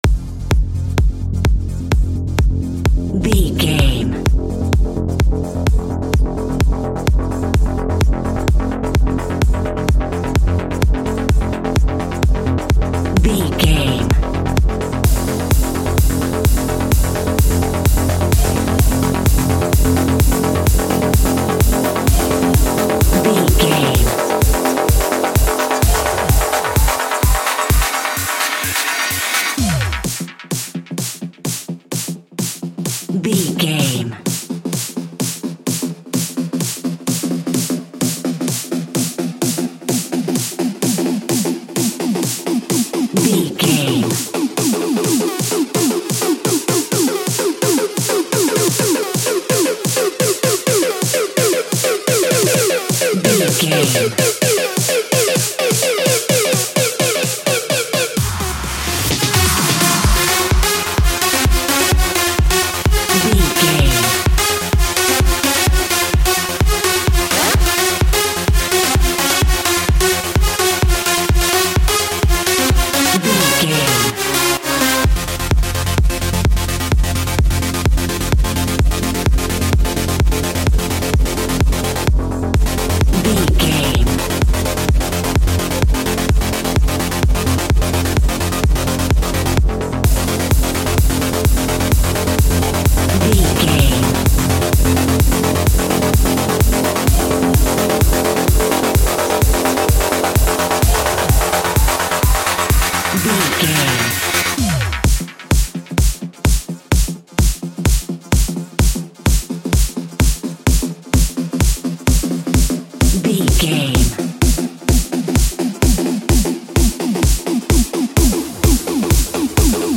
Trance for Adverts.
In-crescendo
Aeolian/Minor
driving
energetic
hypnotic
frantic
synthesiser
drum machine
acid house
uptempo
synth leads
synth bass